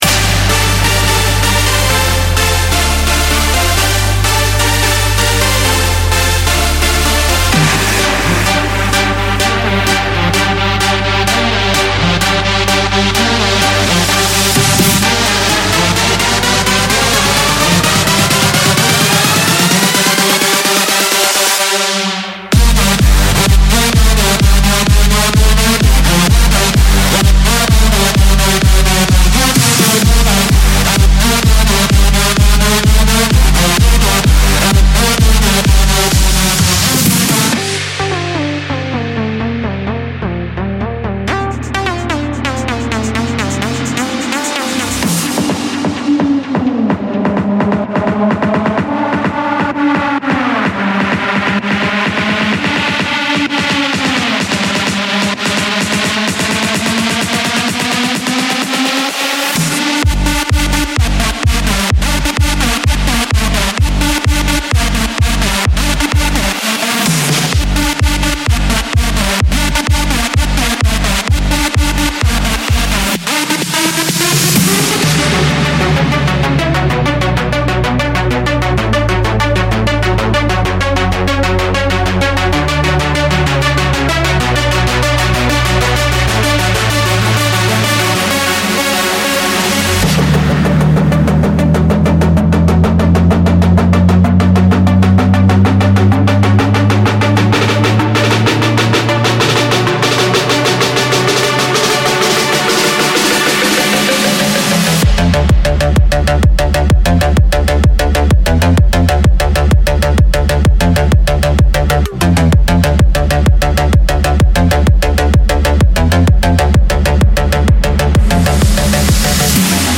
这个包挤满了重击EDM鼓循环，低音循环，合成器循环，强劲的鼓音，FX和填充等等。
Big Room Drum Hits
Mainroom Kicks and Drops
Progressive EDM 2